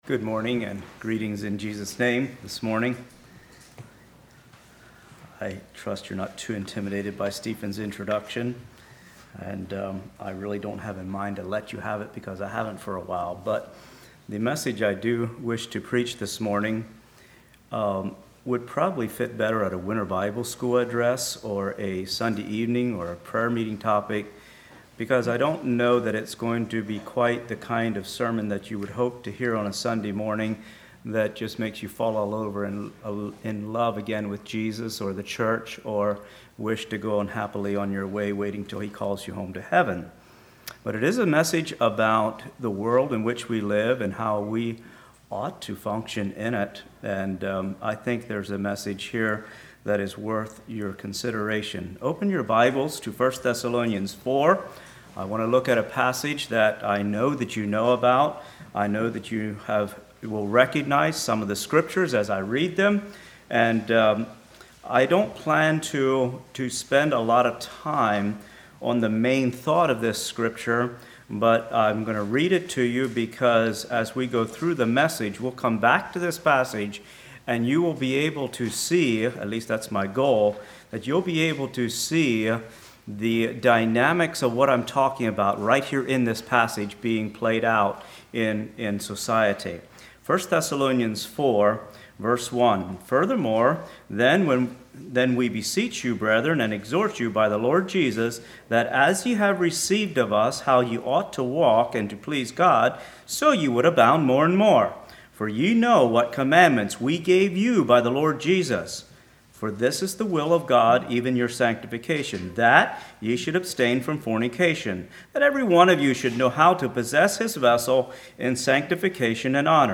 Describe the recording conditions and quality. Social Reserve and Social Media Congregation: Tyrone Speaker